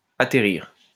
wymowa:
IPA[a.tɛ.ʁiʁ] lub IPA[a.te.ʁiʁ]